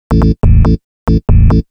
Techno / Bass / SNTHBASS104_TEKNO_140_A_SC2.wav
1 channel